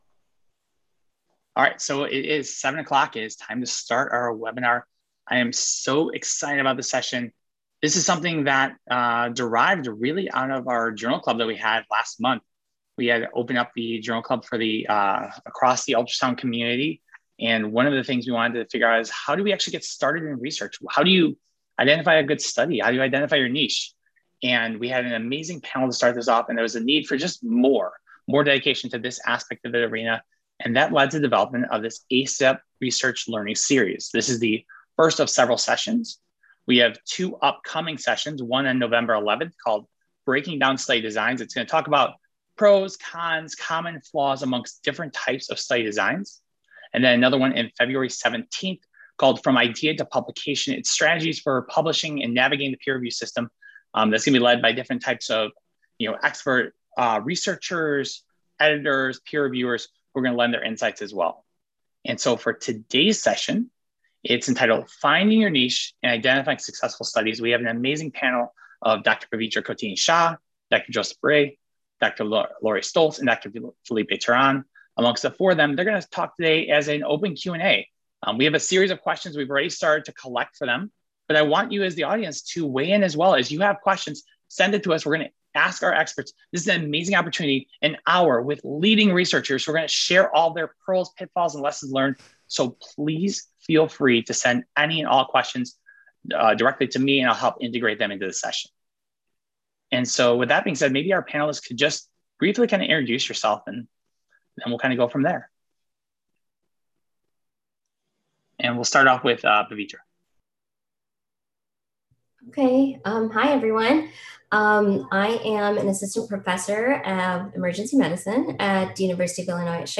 From Idea to Publication: Strategies for Publishing and Navigating the Peer Review System , Webinar (Audio only)